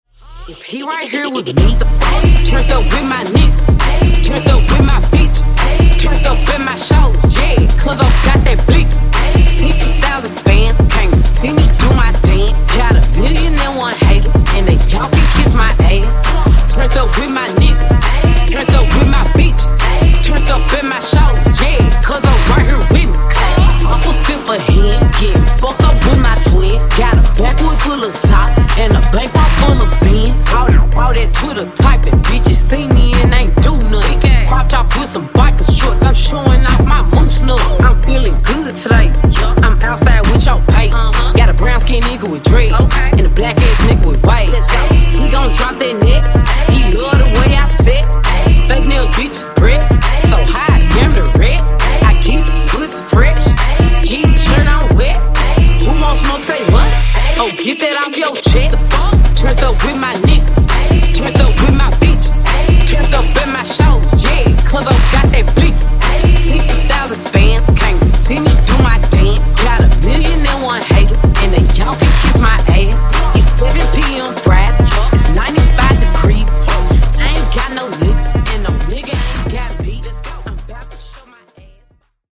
Genres: DANCE , RE-DRUM , TOP40
BPM: 80